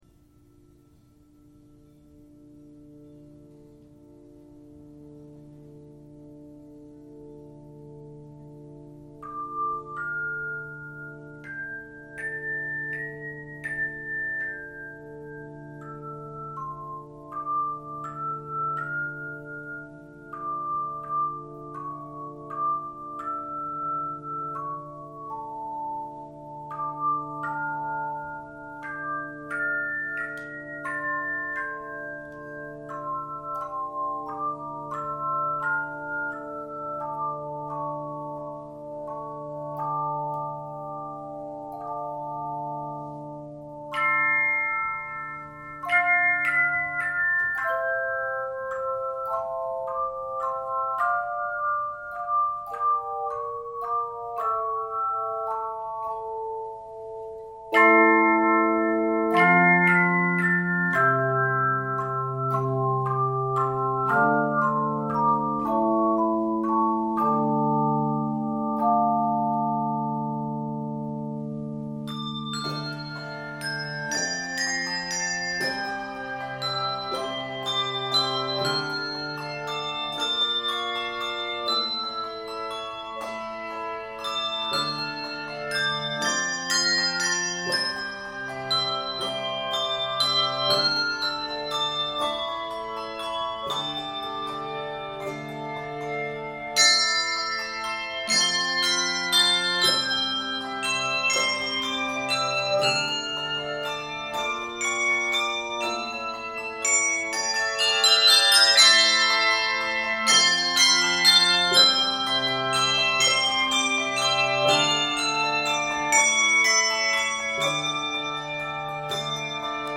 Key of d minor.
Octaves: 3-5